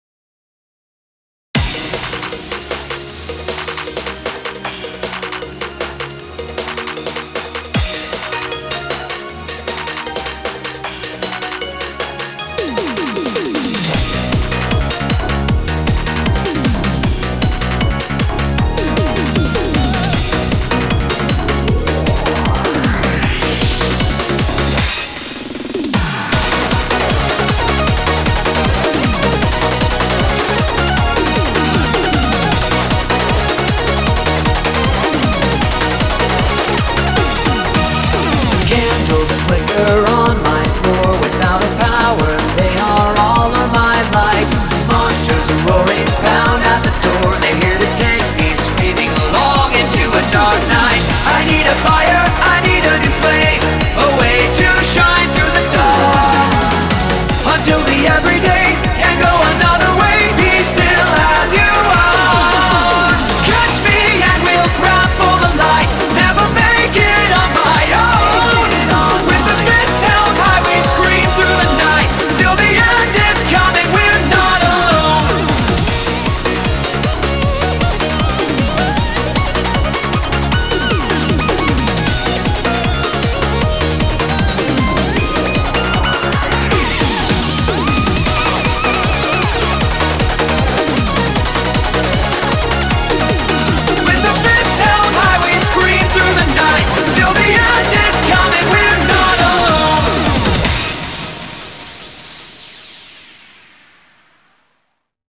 BPM155
Audio QualityPerfect (High Quality)
I'm loving this hard to find dance remix for